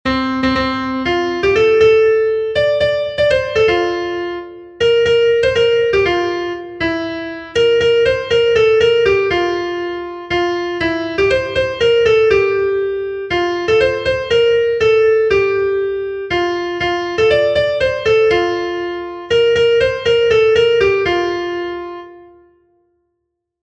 Sentimenduzkoa
Zortziko txikia (hg) / Lau puntuko txikia (ip)
A-B-C-D